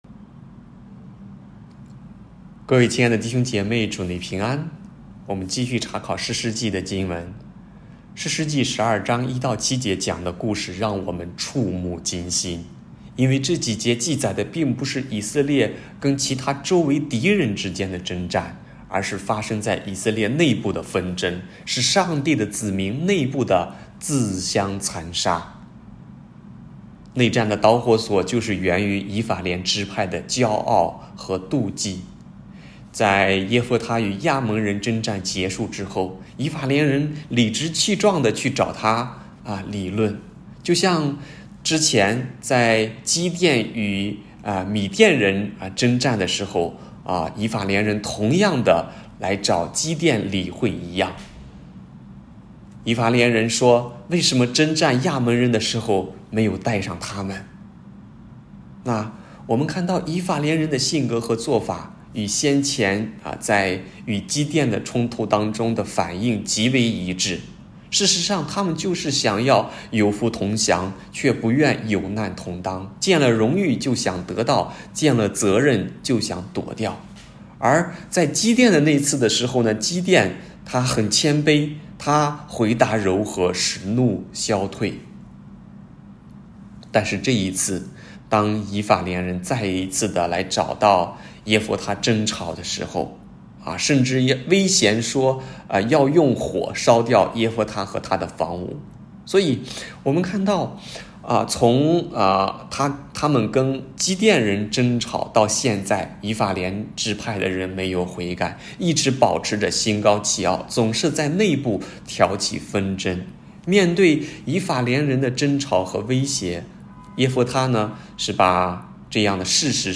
北京基督教会海淀堂